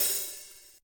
soft-hitnormal.ogg